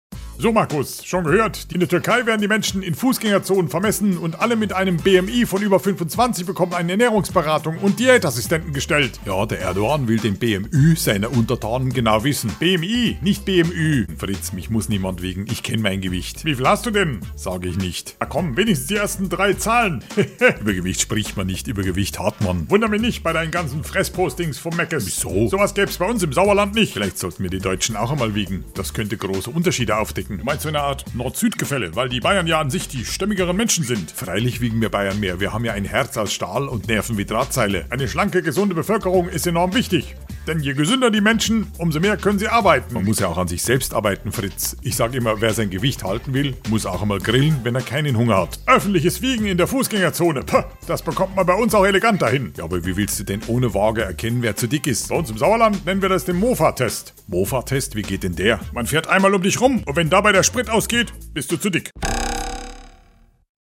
SWR3 Comedy Maggus und Fritz: Bevölkerung wiegen